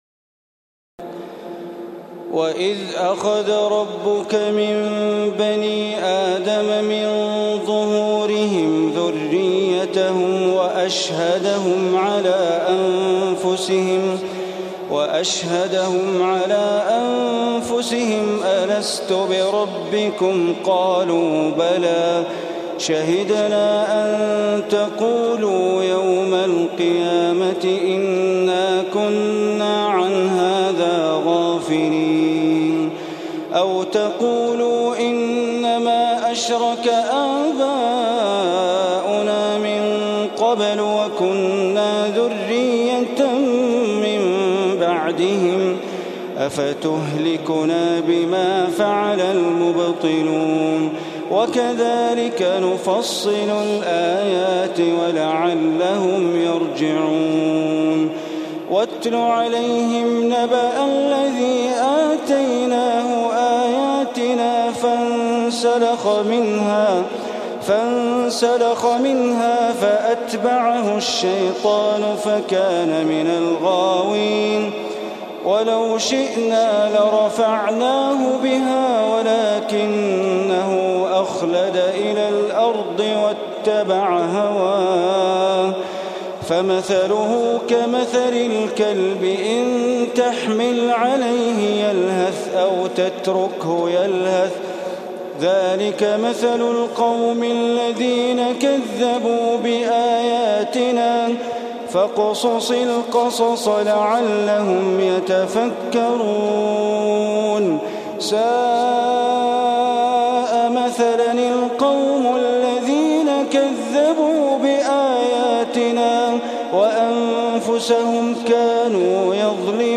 تراويح الليلة التاسعة رمضان 1435هـ من سورتي الأعراف (172-206) والأنفال (1-40) Taraweeh 9 st night Ramadan 1435H from Surah Al-A’raf and Al-Anfal > تراويح الحرم المكي عام 1435 🕋 > التراويح - تلاوات الحرمين